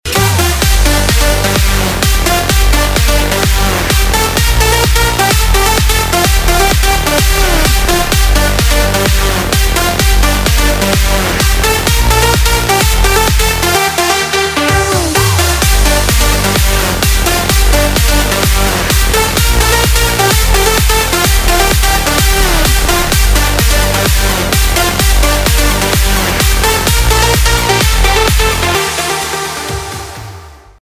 • Качество: 320, Stereo
громкие
зажигательные
EDM
Стиль: electro house, big room